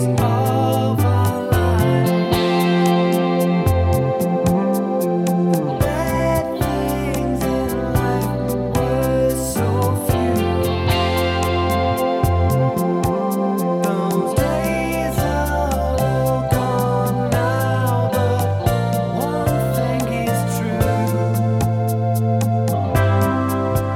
Minus Guitars Rock 4:09 Buy £1.50